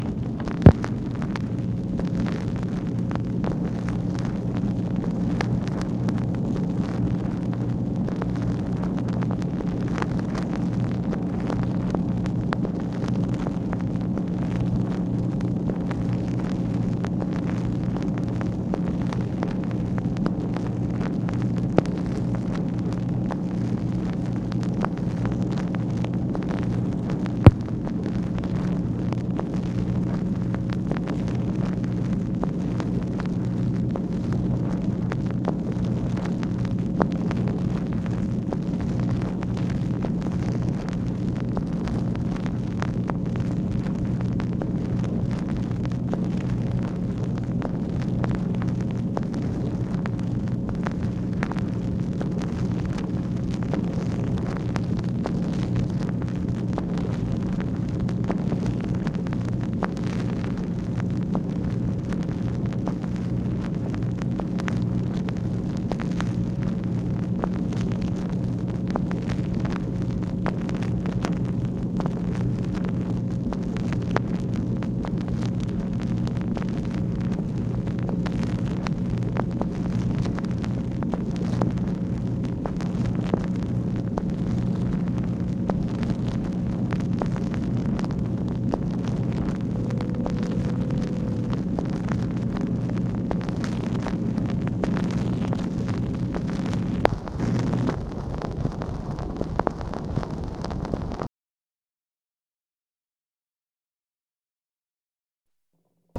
MACHINE NOISE, September 27, 1968
Secret White House Tapes | Lyndon B. Johnson Presidency